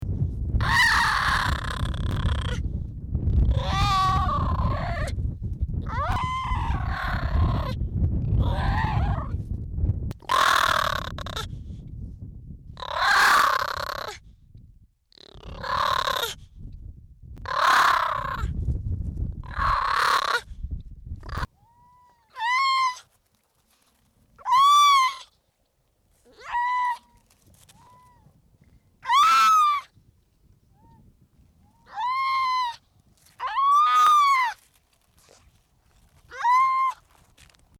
Звуки тюленей, морских котиков
Гренландский тюлень северных морей